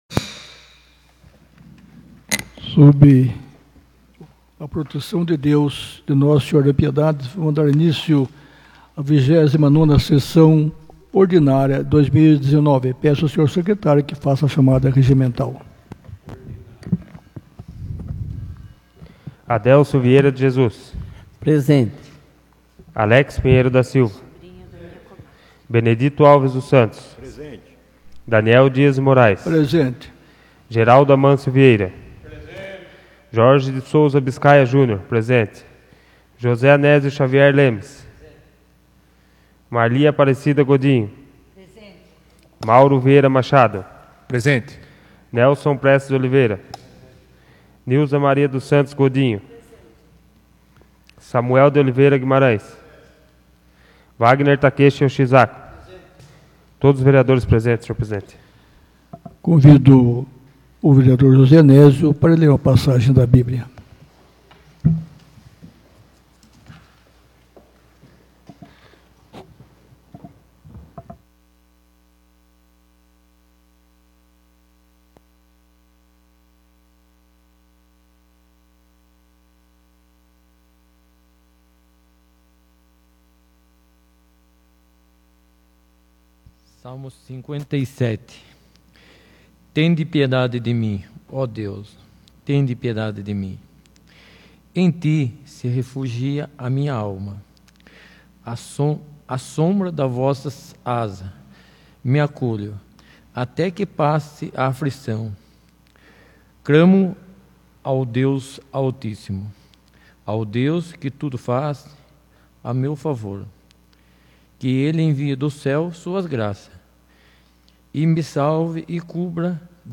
29ª Sessão Ordinária de 2019